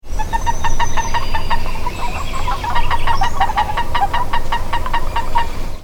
One short call